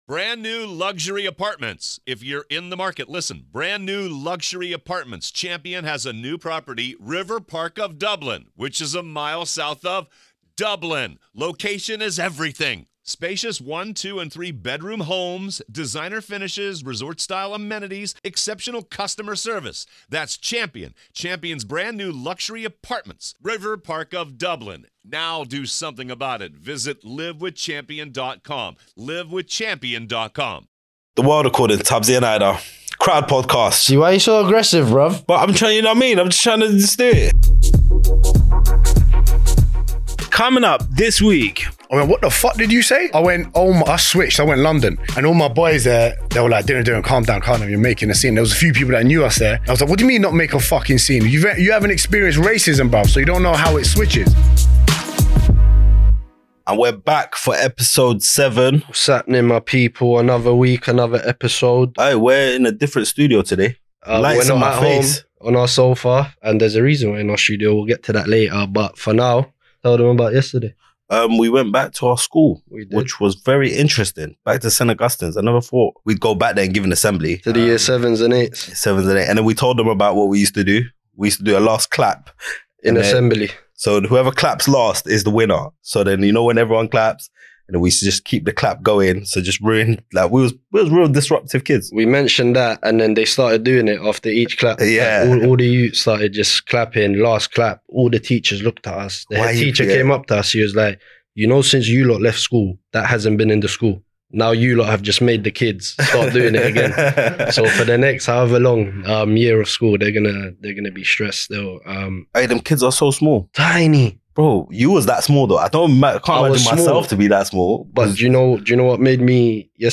Live and direct from the studio, the trio talk about all things health, wealth and relationships.